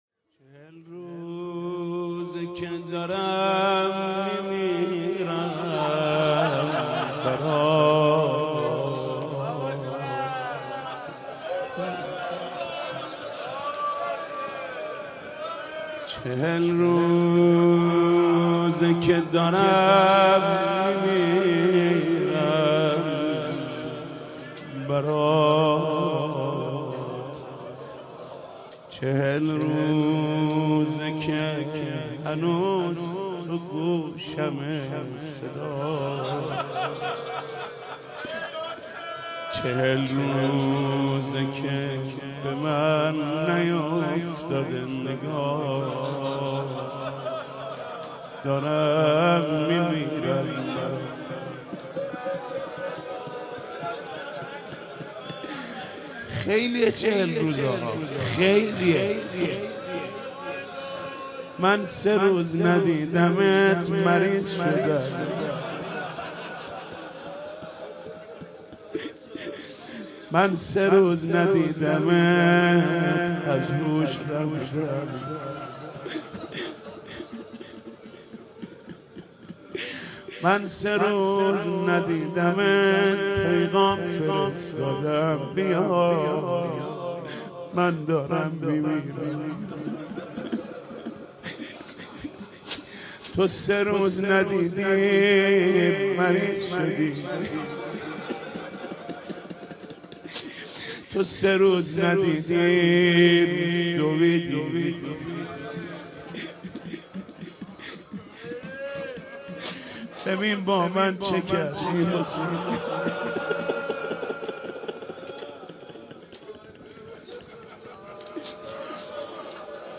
چهل روزه که دارم می میرم برات | روضه